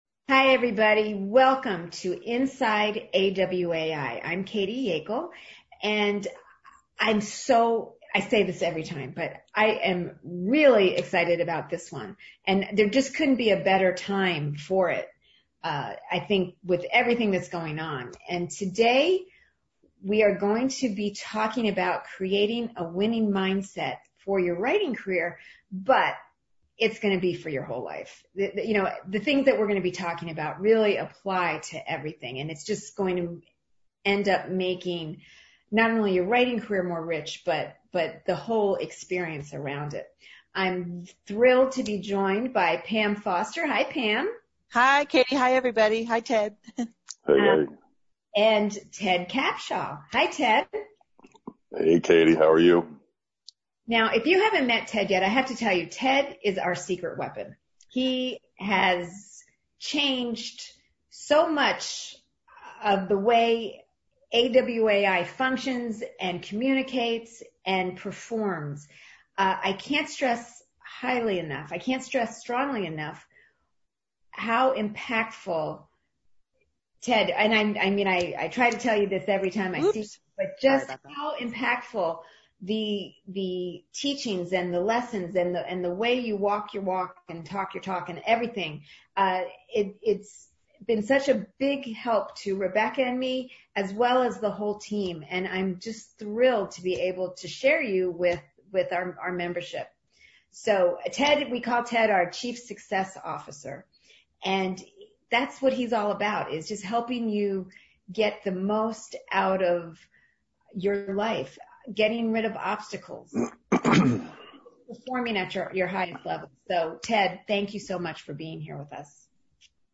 Inside AWAI Webinar and Q&A: Creating a Winning Mindset for Your Writing Career
Then they opened up the lines for a Q&A with listeners.